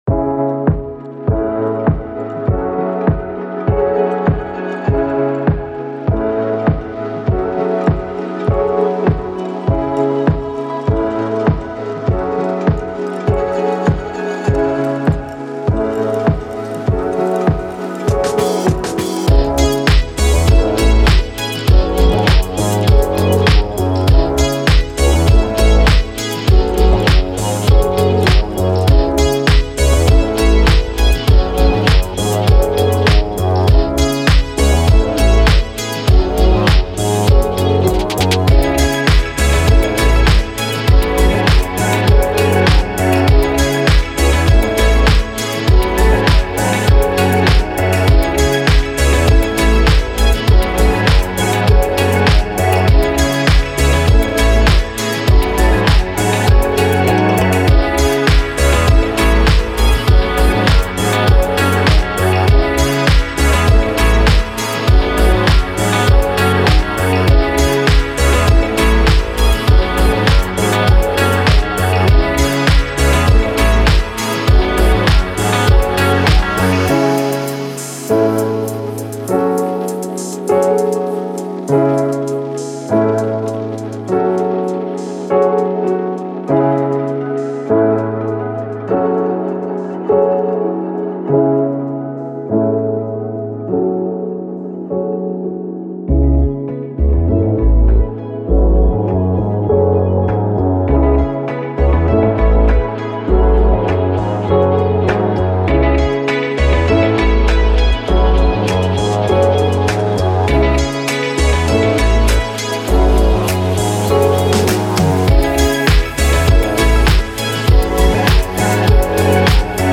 امید‌بخش
الکترونیک
پر‌انرژی